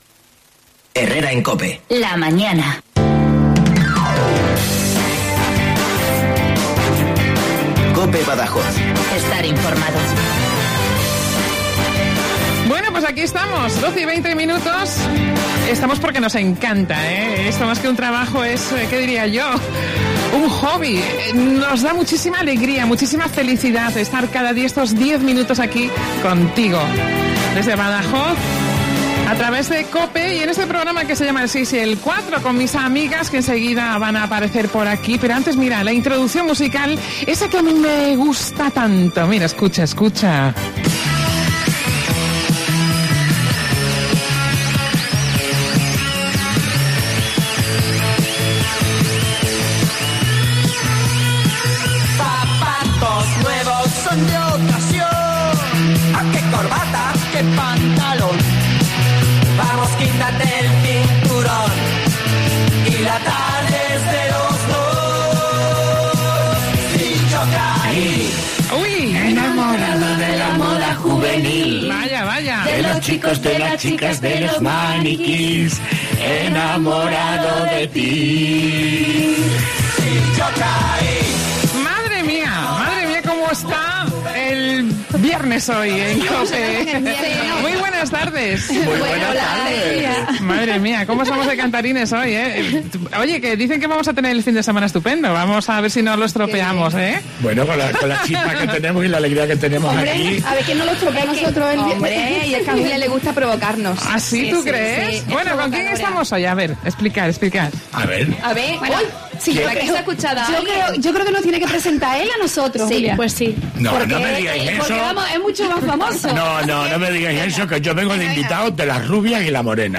Y de cómo nos vemos los extremeños¿Modernos o clásicos?. Hemos sacado los micrófonos a la calle para preguntarlo.